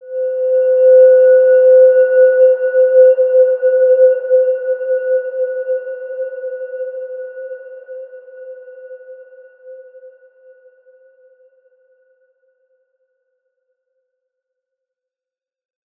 Evolution-C5-p.wav